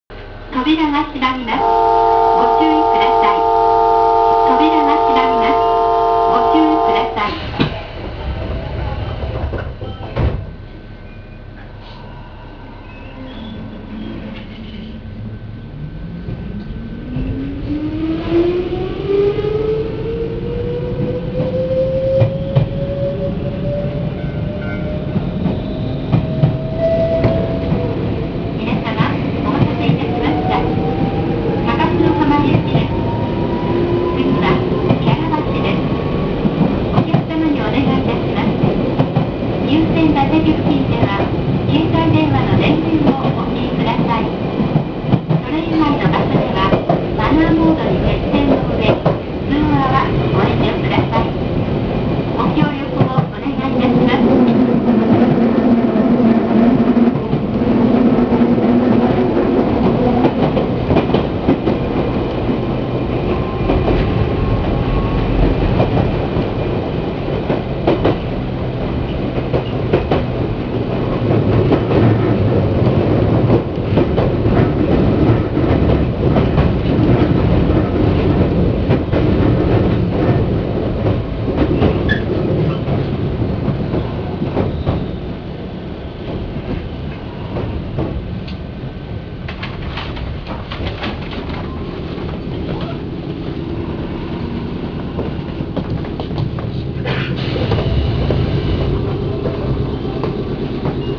・2200系走行音
基本的にはワンマン運転なので自動放送となります。ドアチャイムも装備しているようです。古い車両の上に山岳区間対応車だった名残から、かなり重々しいモーター音となるため、なかなか聞きごたえがあります。